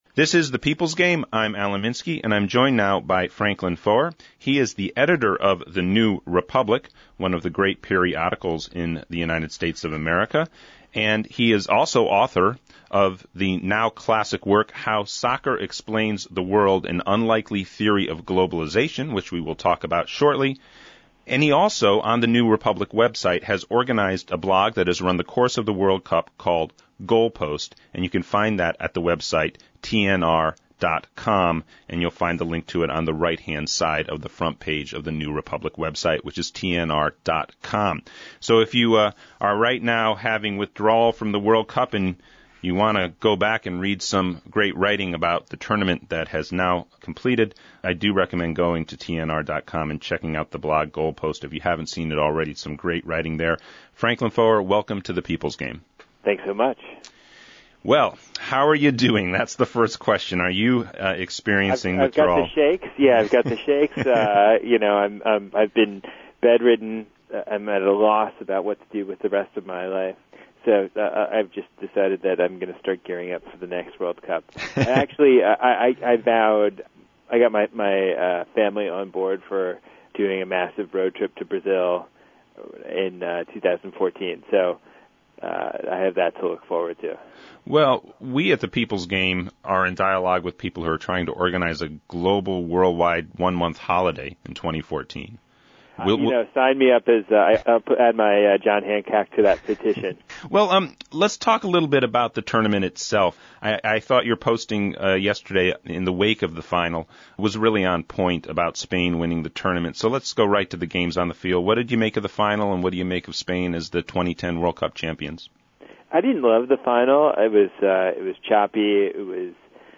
Franklin Foer Interview – The People’s Game Radio